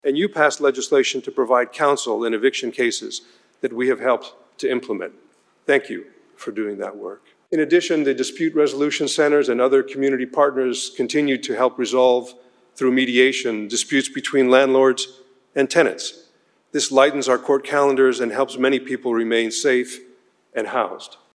WA Supreme Court Chief Justice González Delivers State of the Judiciary Address (Listen/Watch)